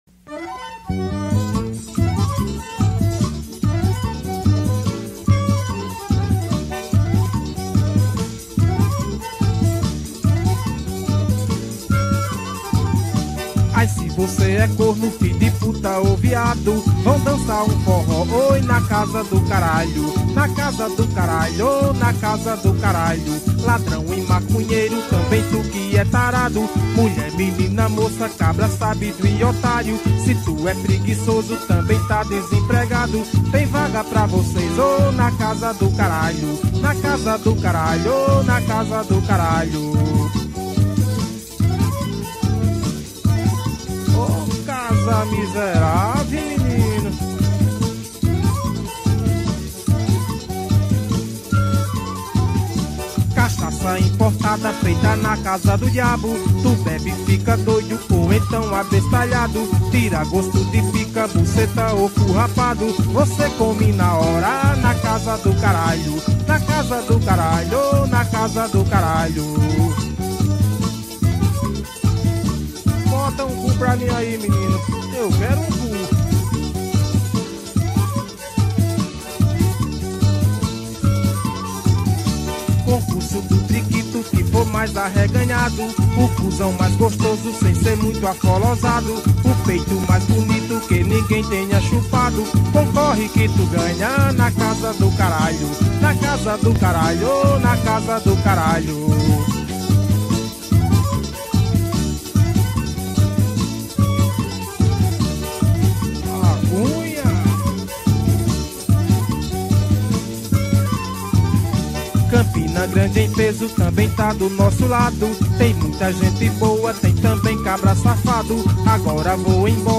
2024-07-22 14:37:03 Gênero: Forró Views